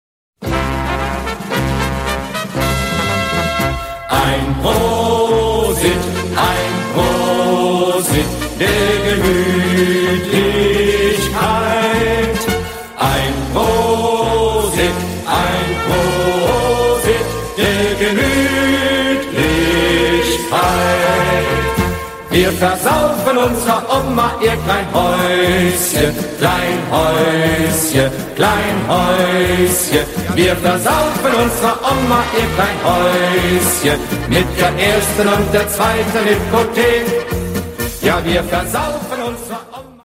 German Drinking Song
Oktoberfest Music